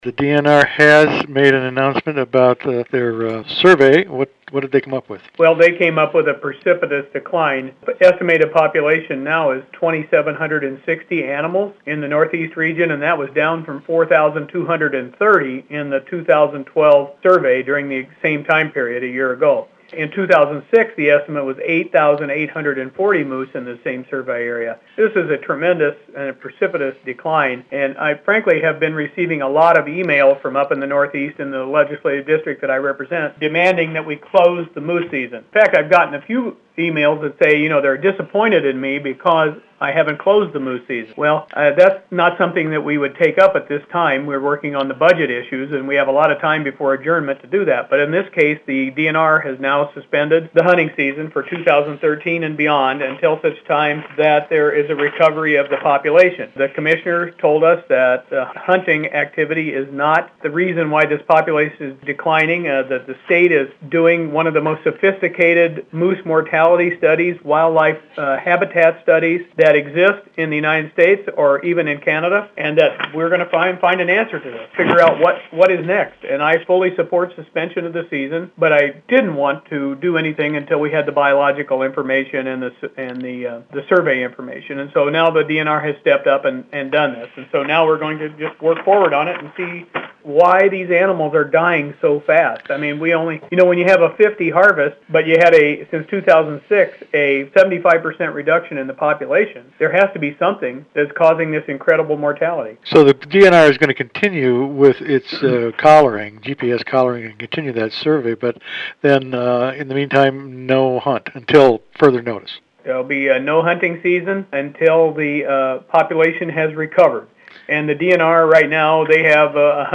District 3A Rep. David Dill said he fully supports the DNR action, and called the population drop “precipitous.”